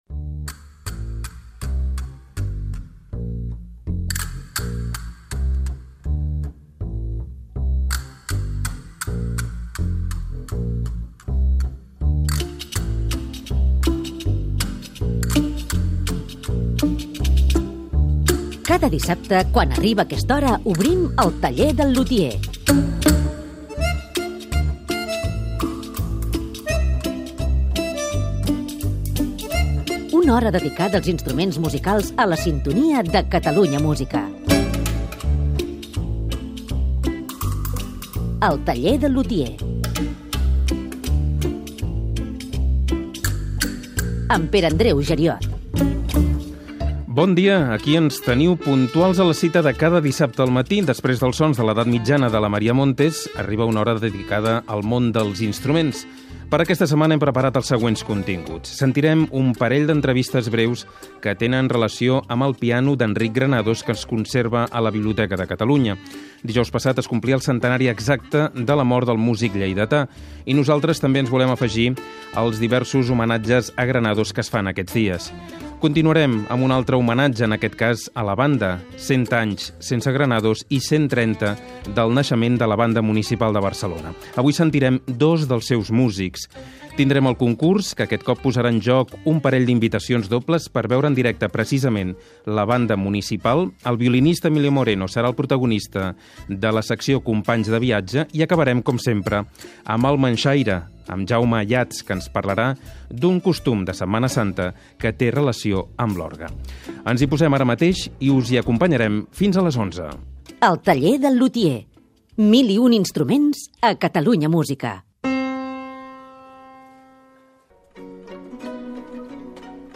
b5690e18de33be0914a35479a0b4bdad81338abc.mp3 Títol Catalunya Música Emissora Catalunya Música Cadena Catalunya Ràdio Titularitat Pública nacional Nom programa El taller del lutier Descripció Careta del programa i presentació amb el sumari.